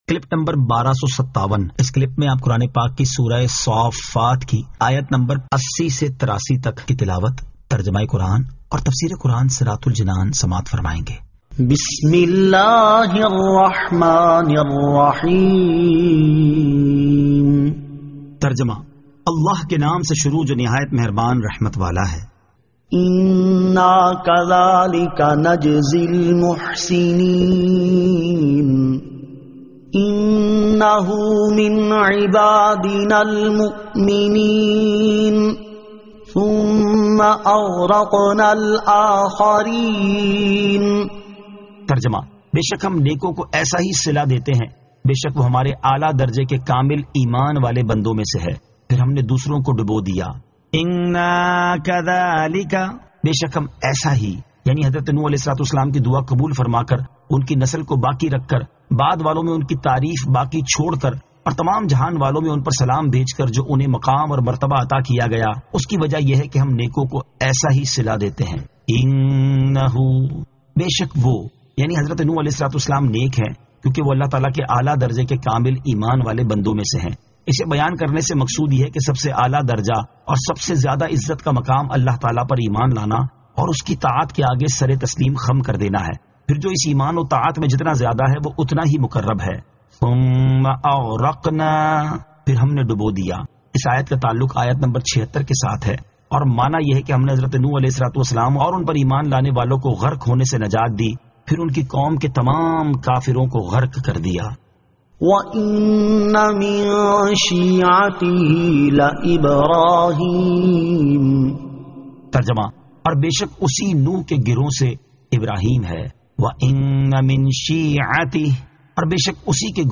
Surah As-Saaffat 80 To 84 Tilawat , Tarjama , Tafseer